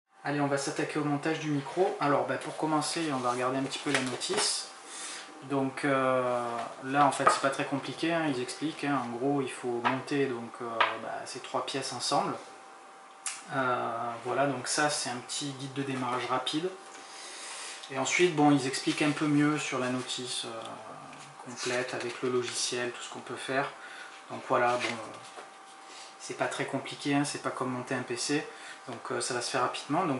Voici un autre extrait avec le micro intégré de ma caméra Sony ZV-E10
On ressent clairement ici la différence entre le micro integré et le micro dédié, la différence avec le Yeti X est plus subtile.